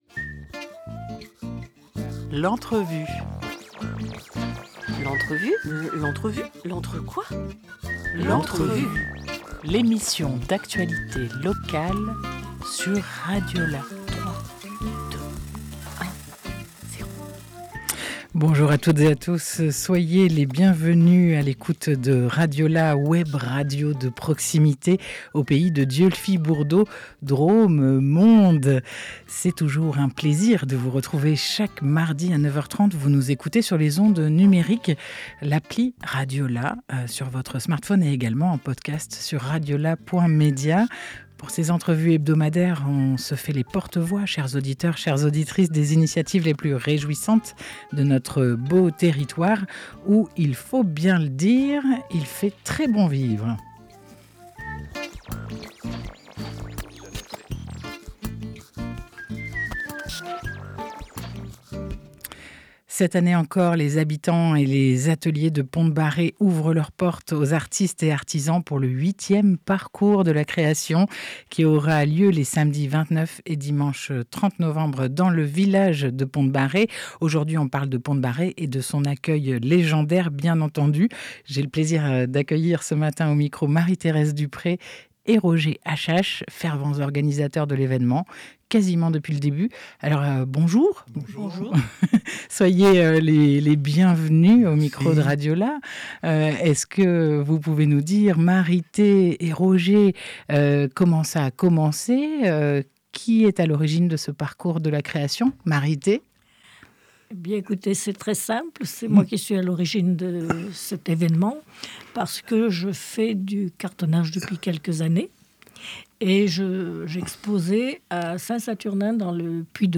11 novembre 2025 11:00 | Interview